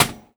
R - Foley 142.wav